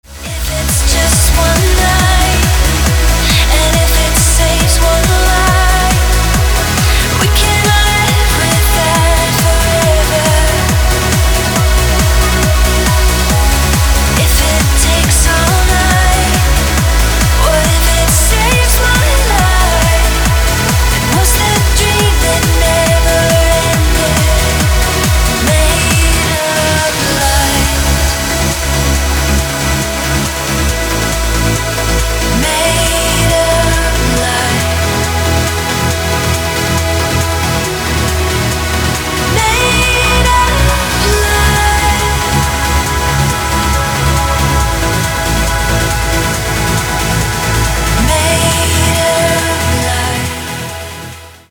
• Качество: 320, Stereo
громкие
женский вокал
dance
EDM
электронная музыка
progressive trance
vocal trance